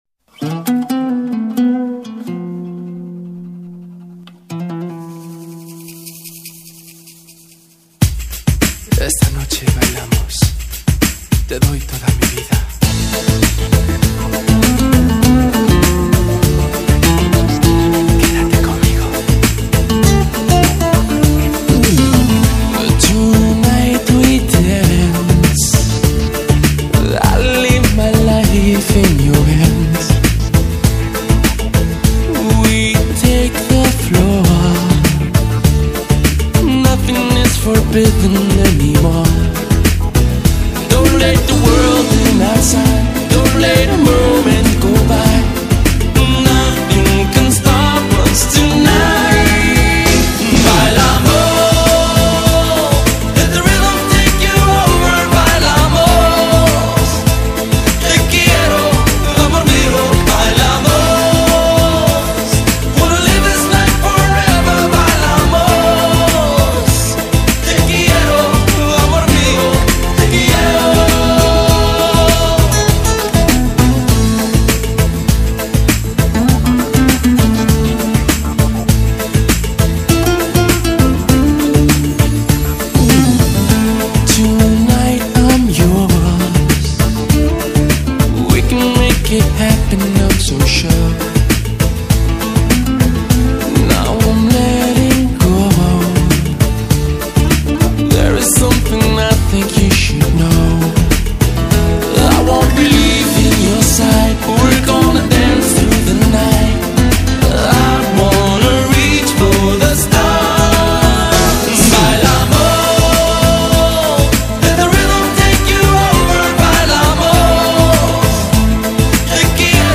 страстной, как огонь, песней